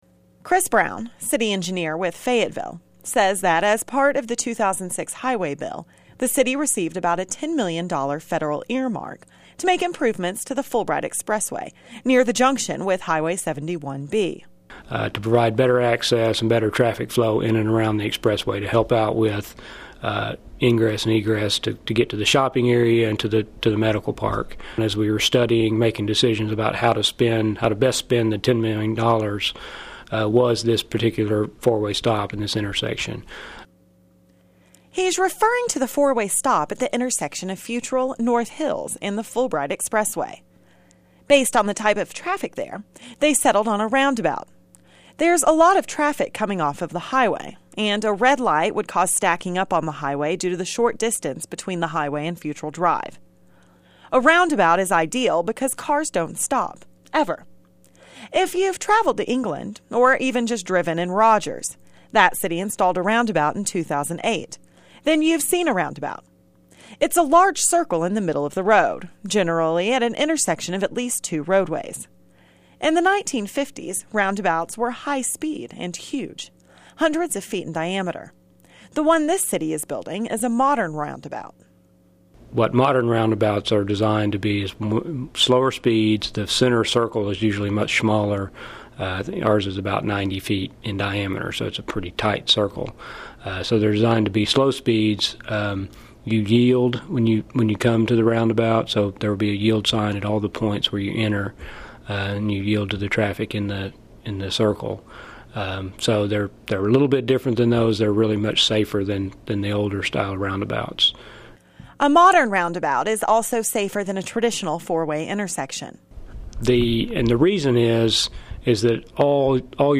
TrafficRoundabouts_0.mp3